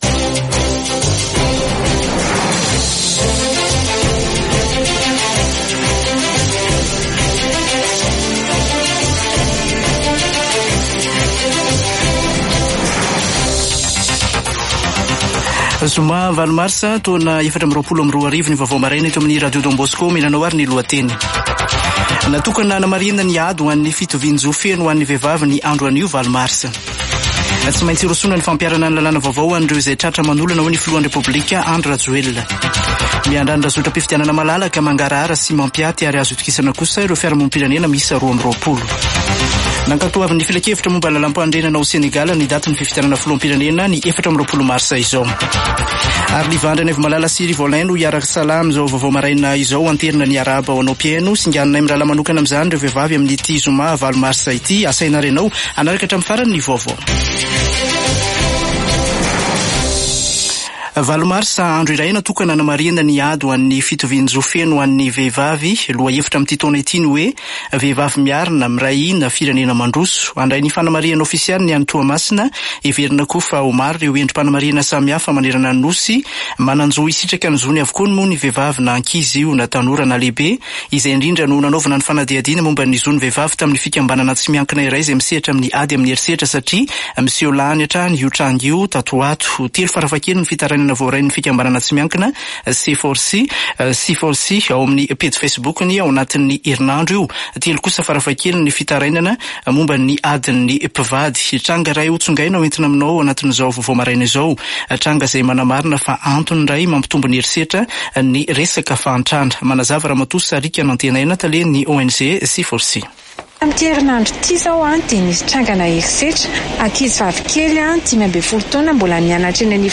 [Vaovao maraina] Zoma 8 marsa 2024